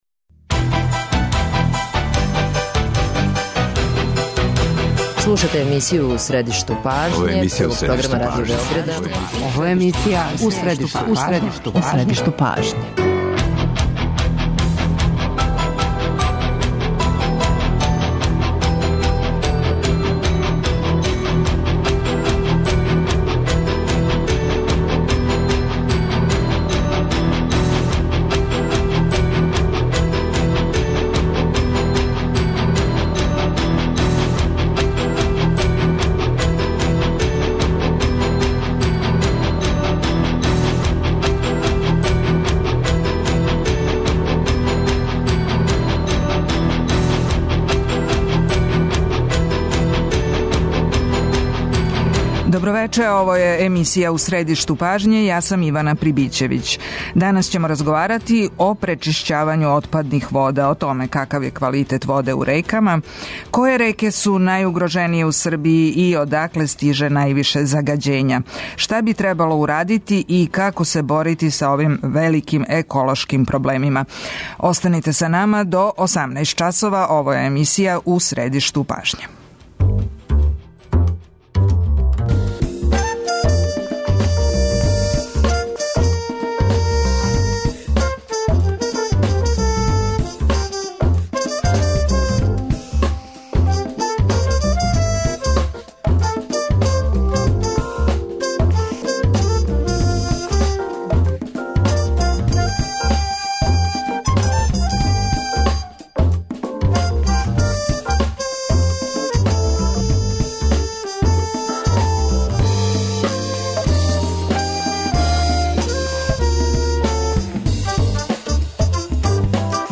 У разговор ће се укључити и саговорници из неколико градова у Србији.